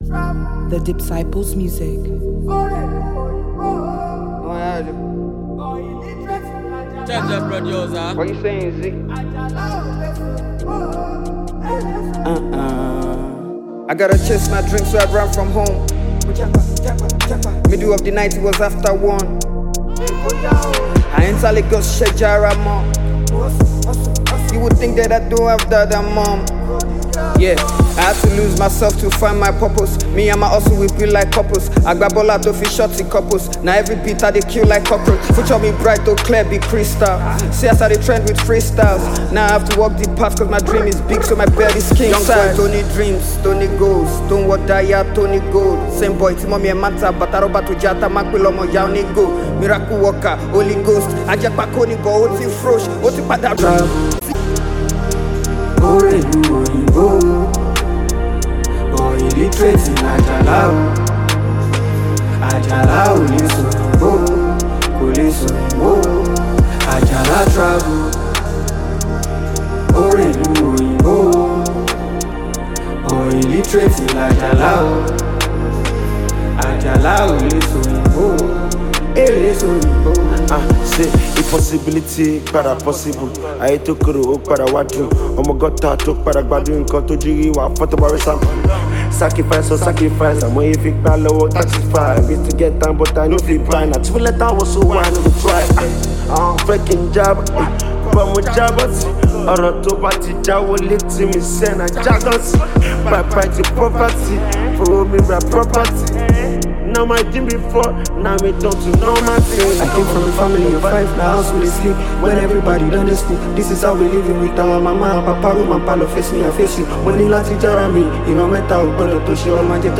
Recording rap artiste
ballad record